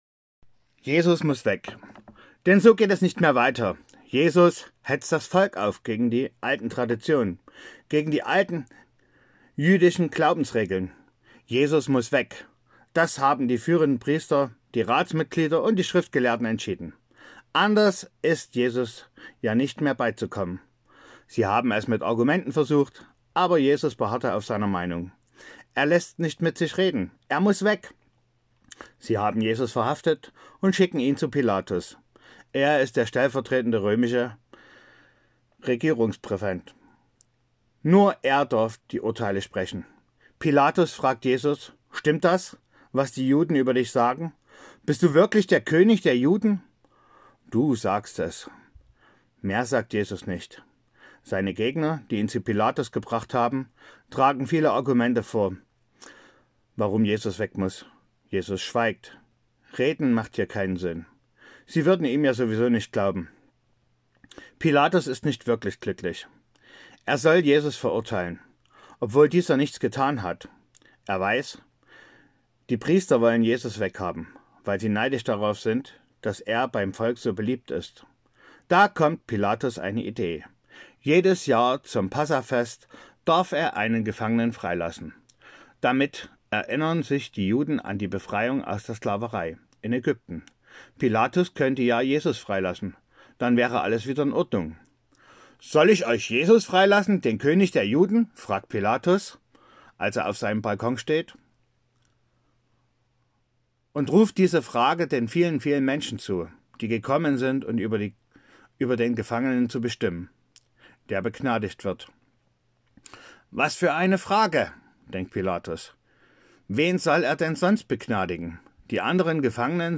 Hier die Hörspielgeschichten